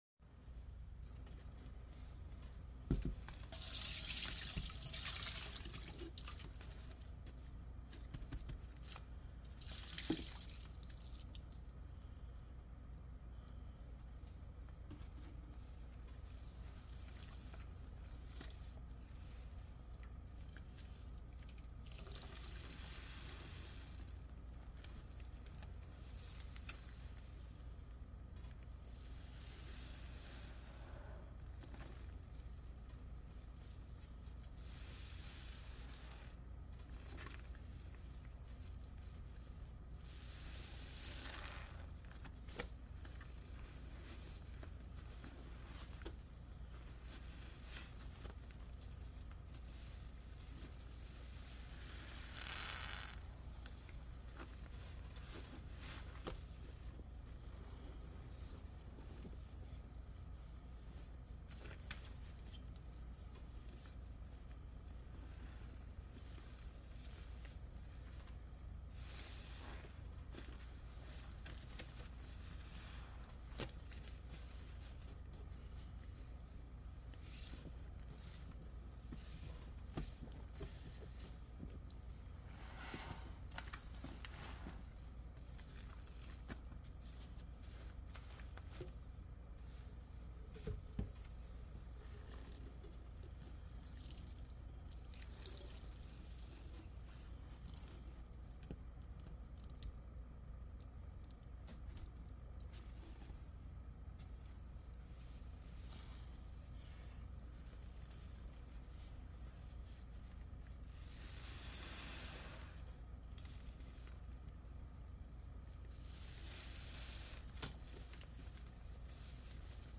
Product dump with soaked sponge sound effects free download
Product dump with soaked sponge in Fabuloso 🫧🫧🫧 These suds be suddin' - dumped on some saved laundry and the blue bowl for that bright color I love!!